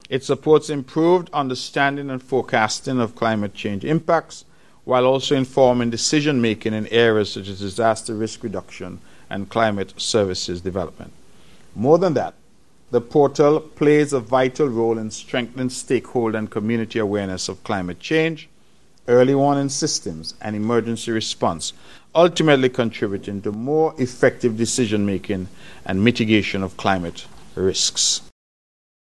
Hon. Mark Brantley.